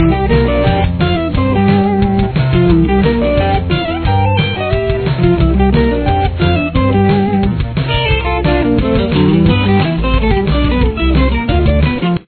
Intro Riff
Guitar 1